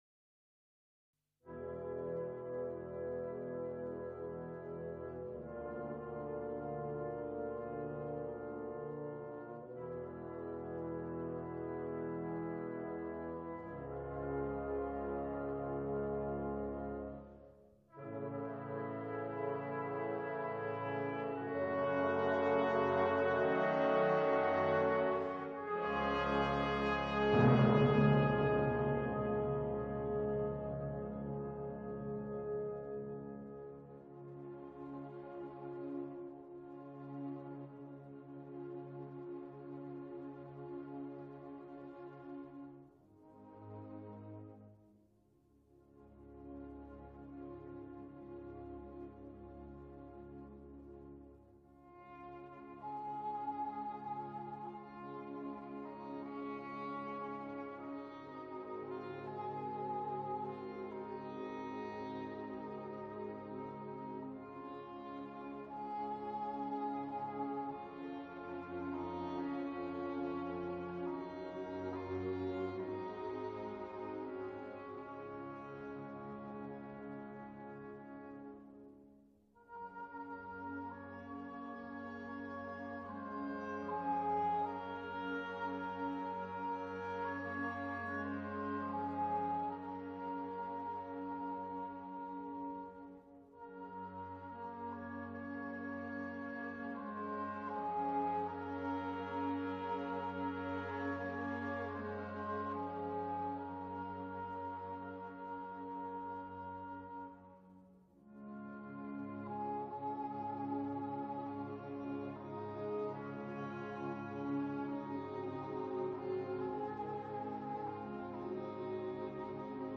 After the interval Dvorak’s Number 9 commonly known as “the New World ” symphony. In parts reminiscent of Gershwin (in my uneducated opinion) and containing the very lovely Largo.